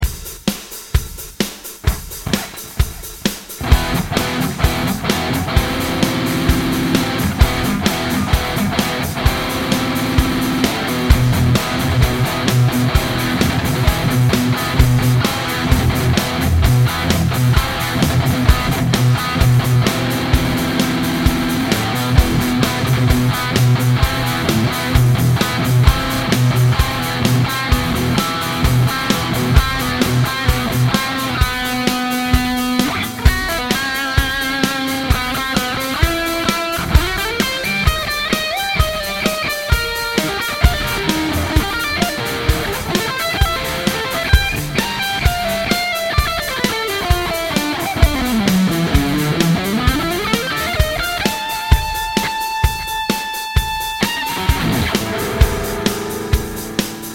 Just using the Session Drummer 3 drummer/kit/pattern(s) for the moment. And with some Guitar Rig 5 preset, I forgot which one.